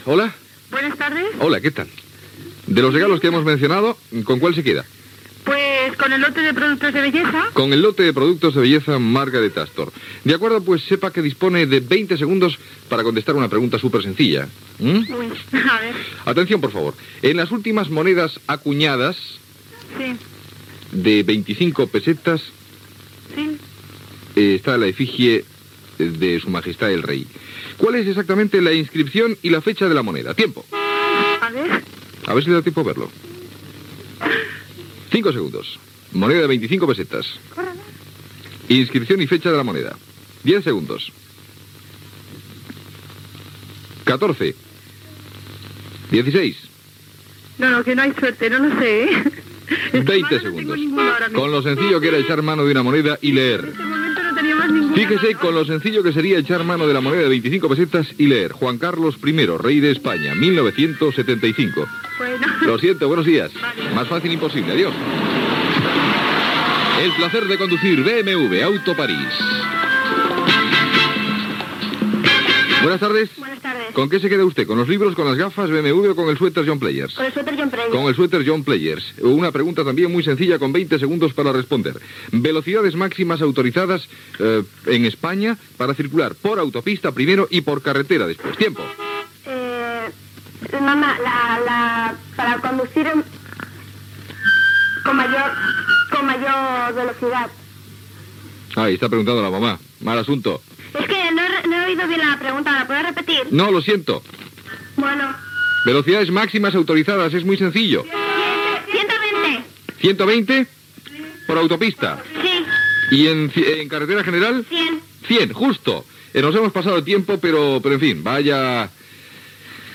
Concurs de preguntes relacionades amb l'automoció i participació telefònica